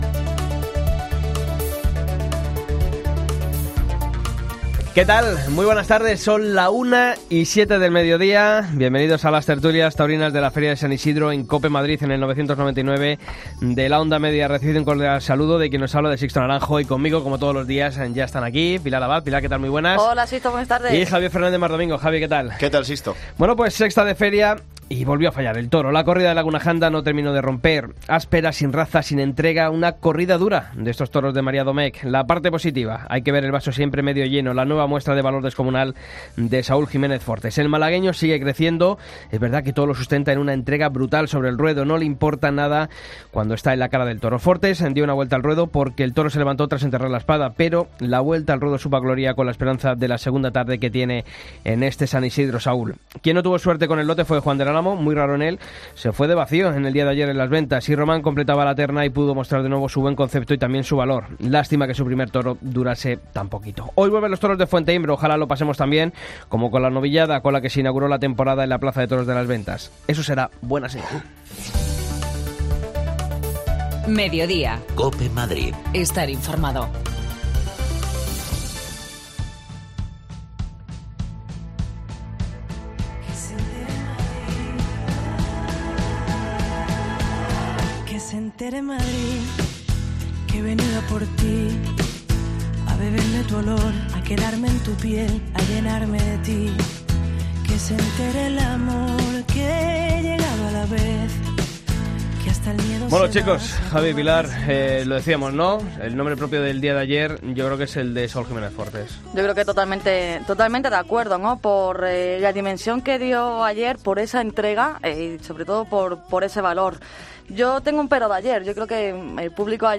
Tertulias Taurinas de COPE Madrid OM